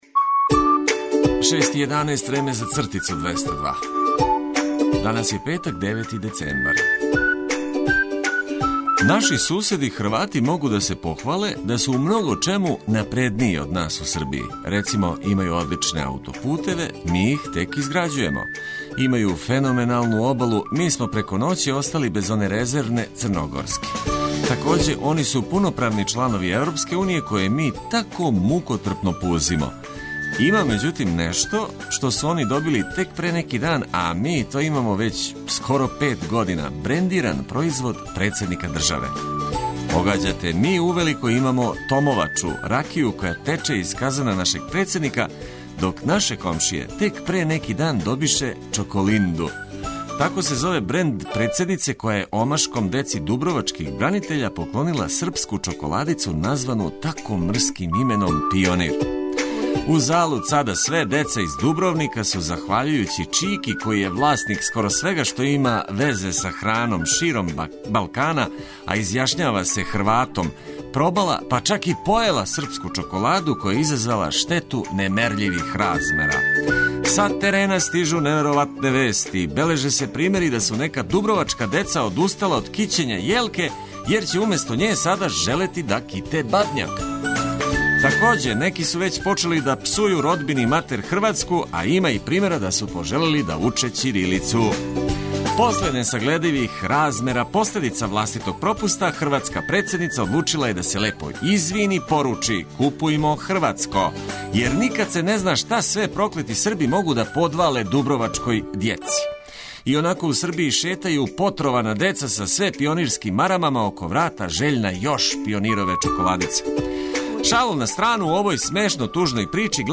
Будимо се овога јутра уз лепе приче добрих људи и музику за намигивање викенду живећи у стварности коју мењамо на боље.